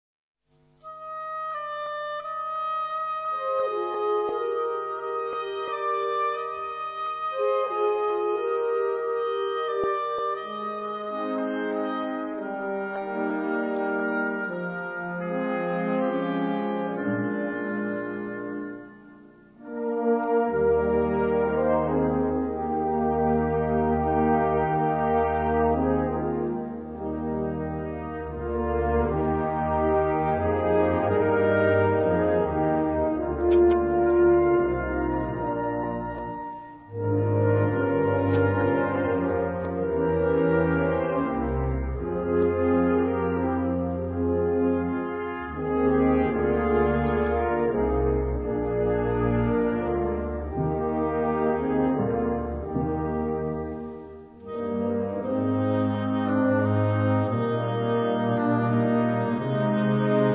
Gattung: Festliche Musik
Besetzung: Blasorchester
in der die Trompete eine Hauptrolle spielt